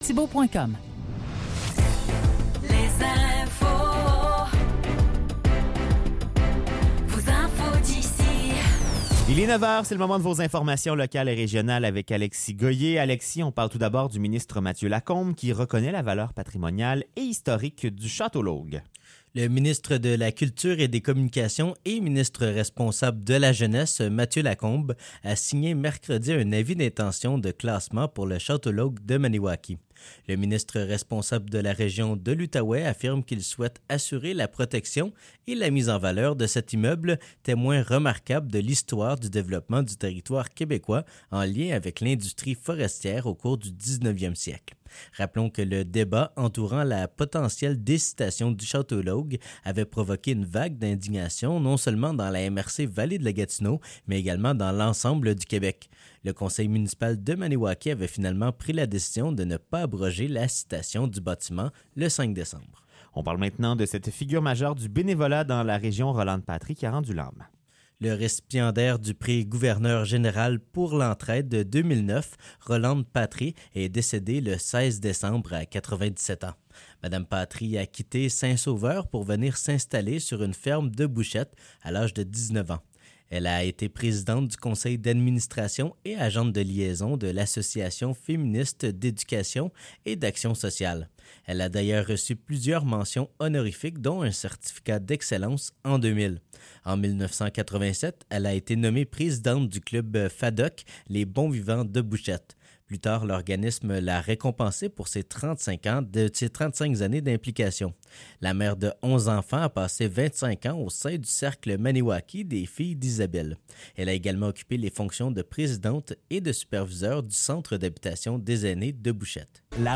Nouvelles locales - 26 décembre 2023 - 9 h